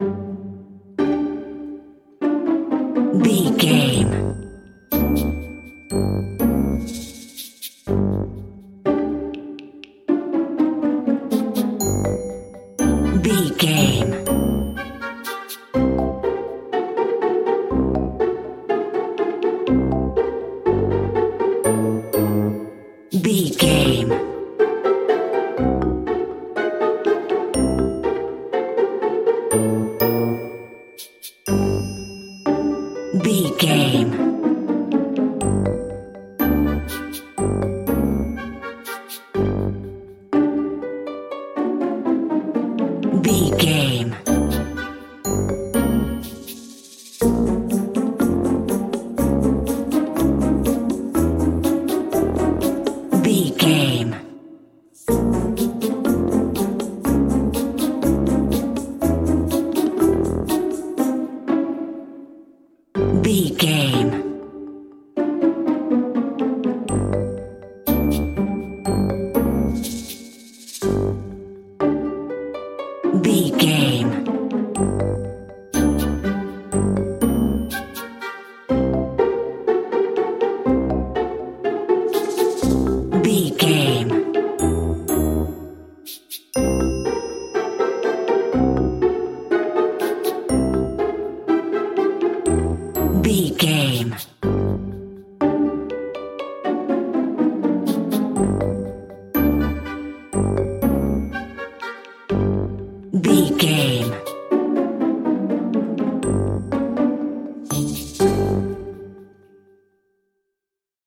Lydian
pizzicato
strings
mallets
glockenspiel
marimba
mellow
playful
bouncy
cello
harp
oboe